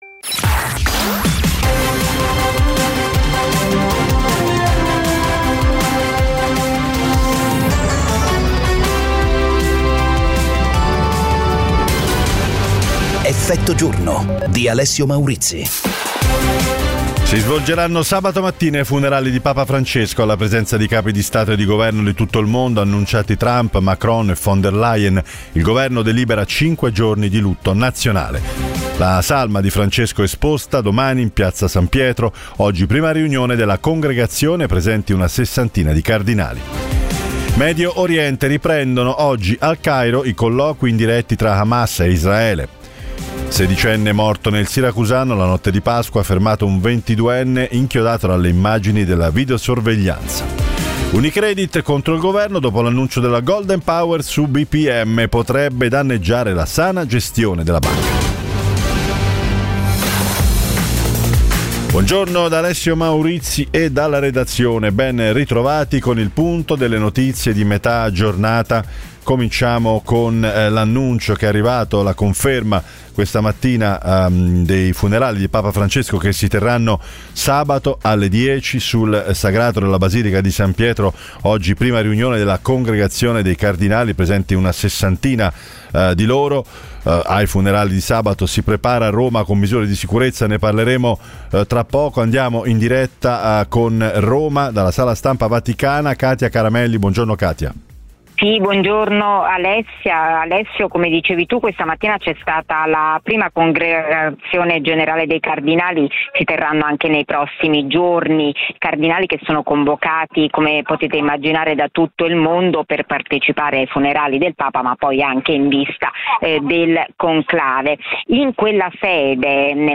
Effetto giorno è la trasmissione quotidiana che getta lo sguardo oltre le notizie, con analisi e commenti per capire ed approfondire l'attualità attraverso ospiti in diretta e interviste: politica, economia, attualità internazionale e cronaca italiana.